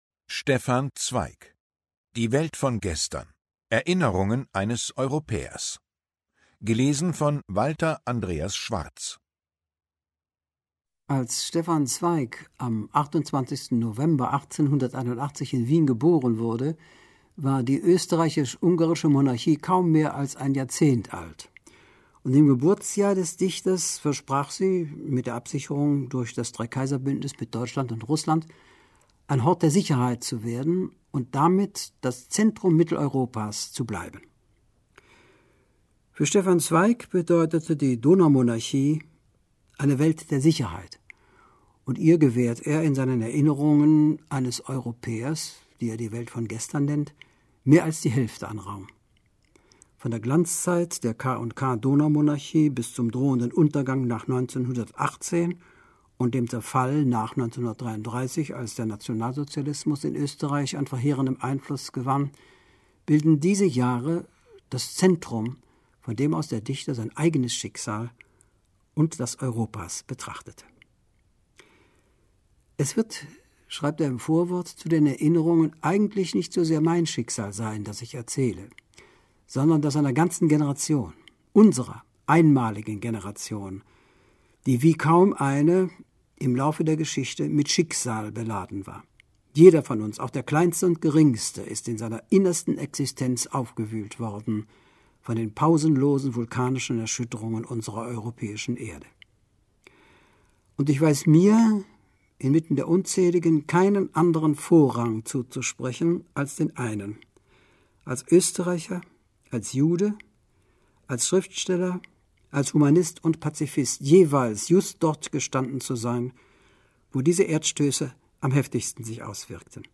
Lesung mit Walter Andreas Schwarz (1 mp3-CD)
Walter Andreas Schwarz (Sprecher)